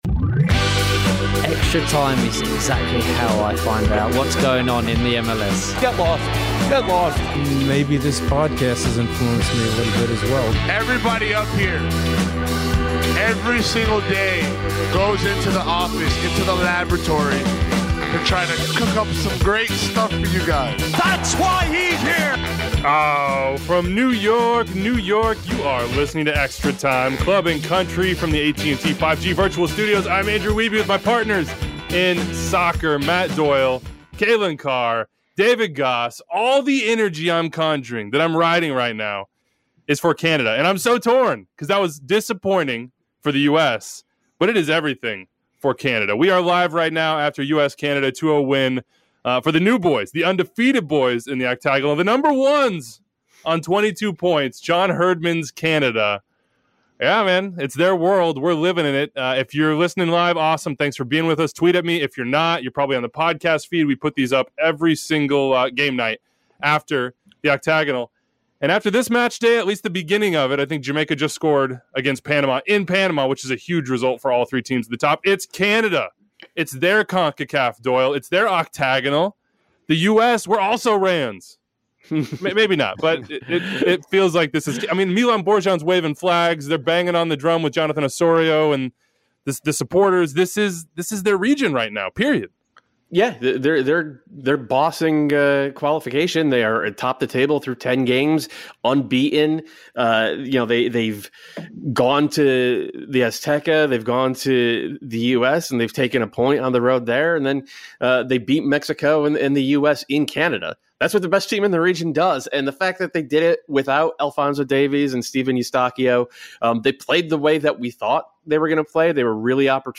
The guys gather for final whistle reactions following a massive W in Hamilton for Canada, the best story in World Cup Qualifying and the best team in Concacaf.